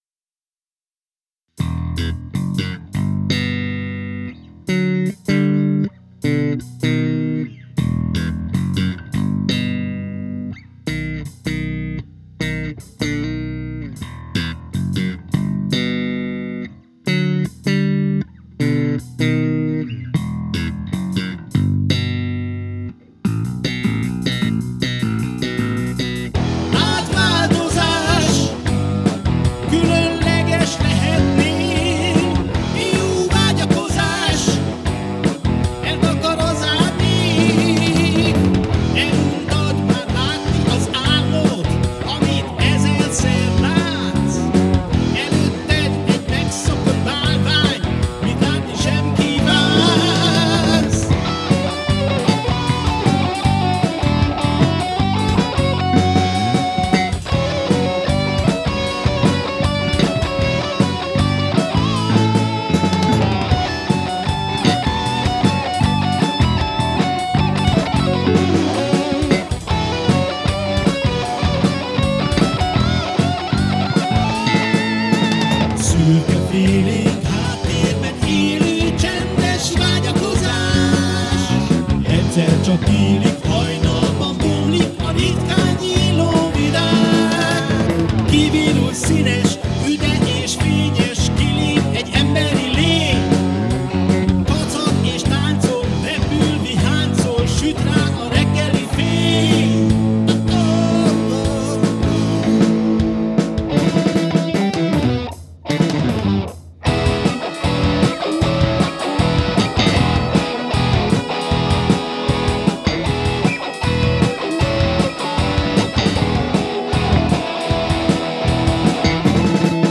Ez a verzió az eredetihez képest kicsit más, mert a szöveget, a szólókat és kicsit a szám szerkezetét is módosítottam. A hangszerelés is kissé modernebb lett.
Key: Em